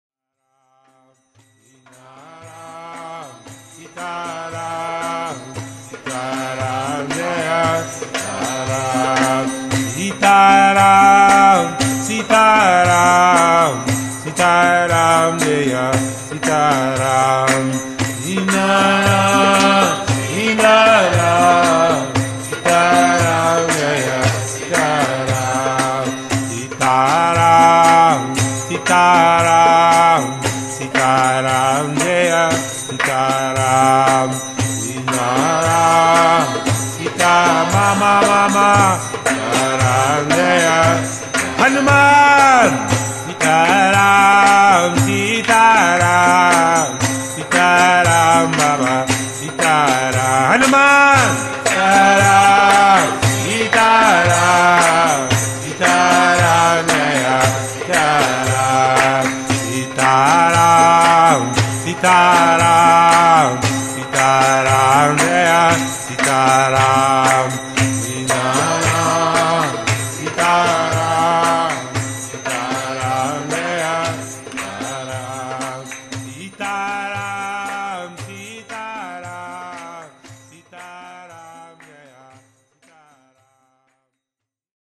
Live Kirtan Chanting CD
This is a live recording of one these Kirtans.